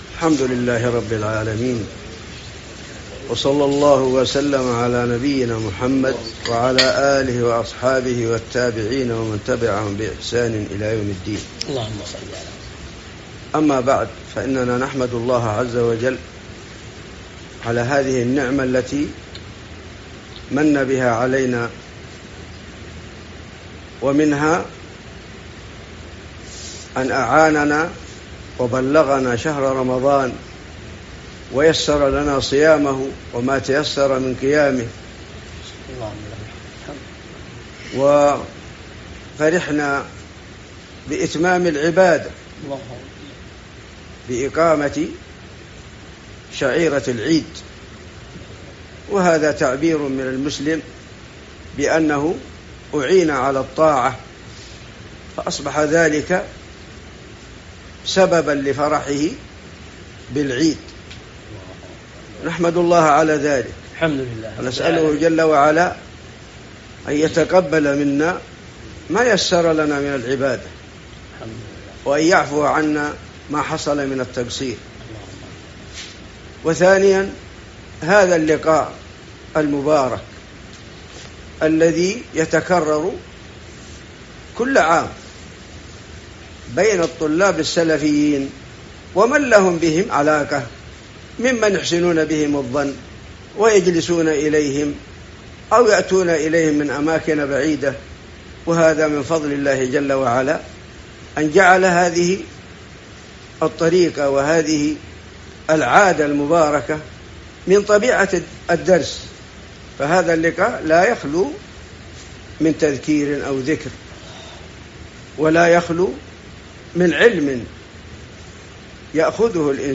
لقاء المعايدة